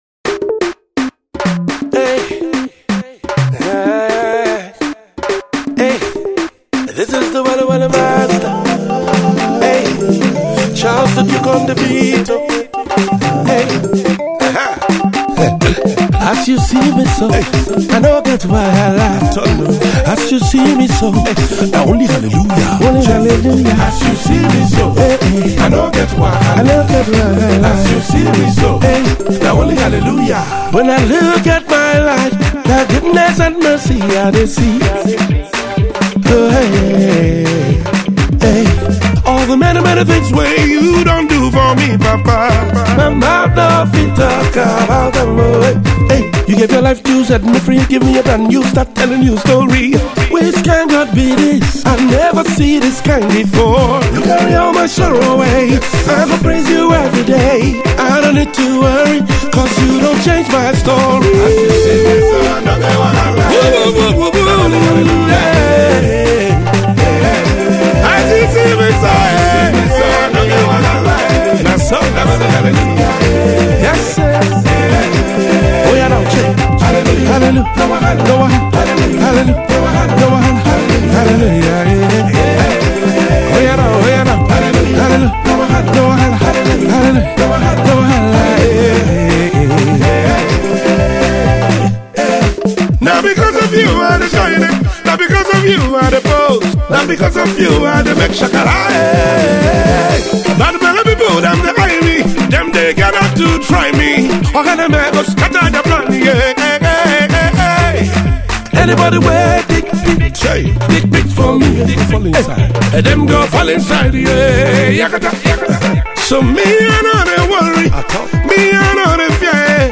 gospel singer
this song is mixed with an Afro-Pop flavour.